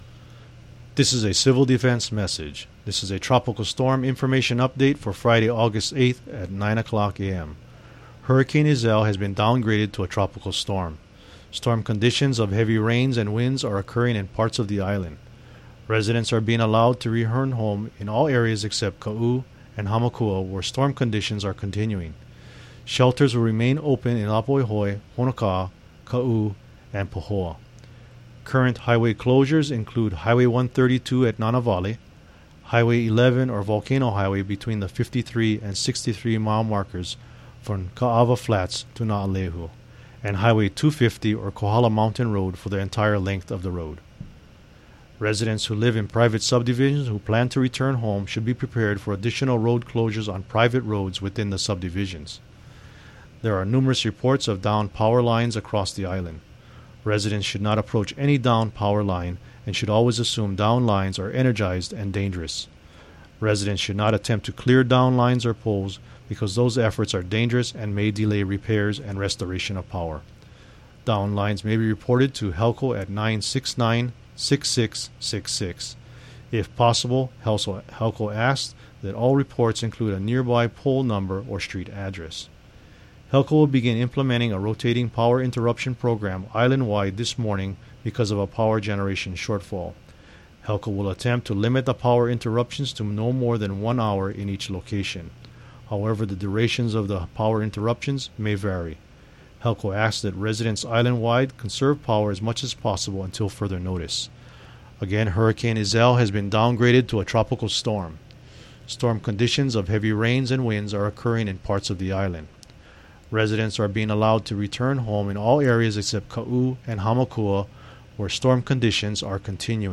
Hawaii County Civil Defense message (Aug. 8 at 9 a.m.)